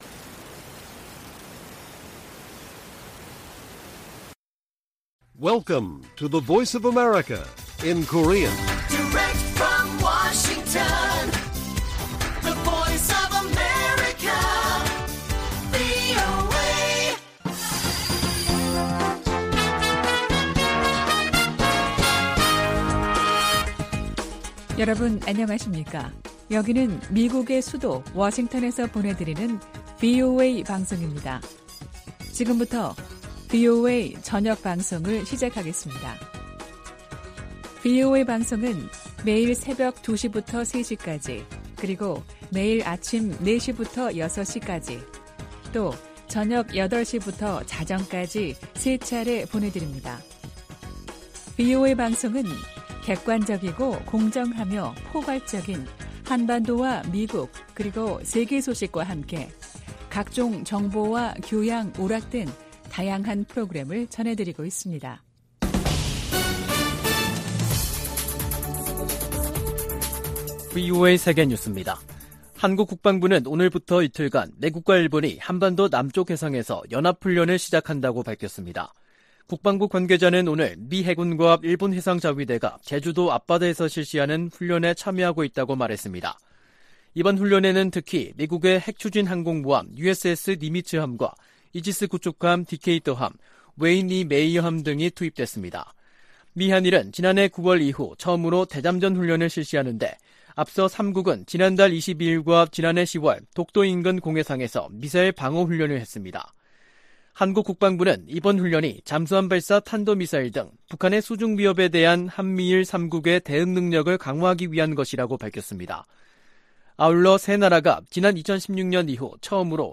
VOA 한국어 간판 뉴스 프로그램 '뉴스 투데이', 2023년 4월 3일 1부 방송입니다. 미 상원이 대통령 무력사용권 공식 폐지 법안을 가결했습니다.